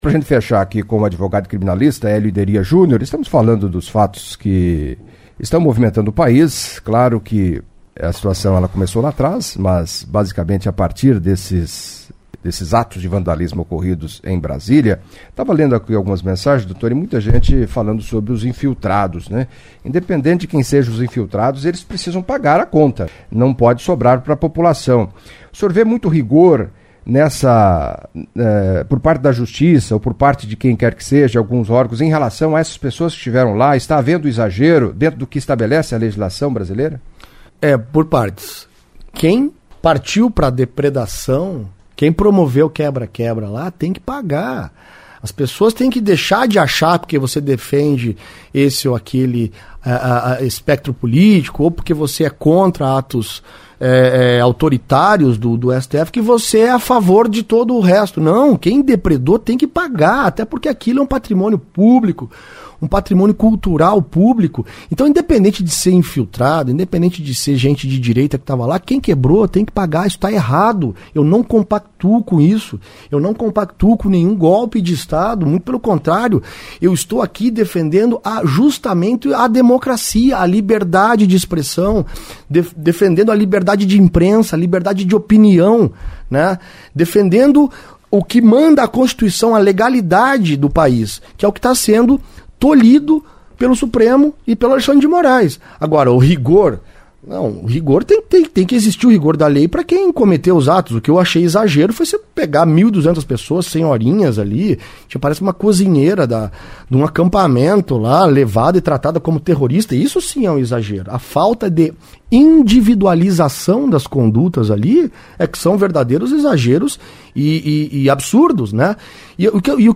Em entrevista à CBN Cascavel